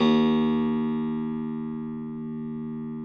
53r-pno03-D0.aif